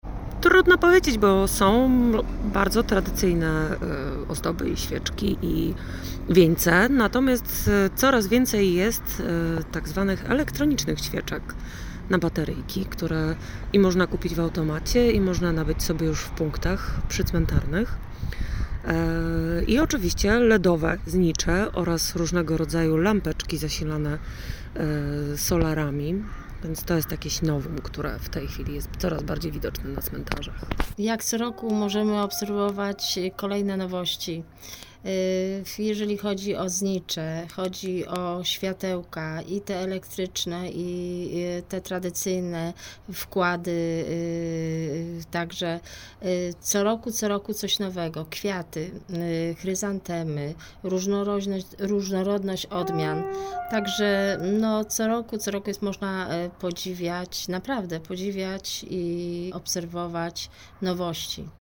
Zaglądamy na parafialny cmentarz św. Jacka na wrocławskich Swojczycach, pytając wrocławianki i wrocławian o to jak zmieniają się cmentarze.